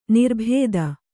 ♪ nirbhēda